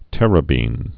(tĕrə-bēn)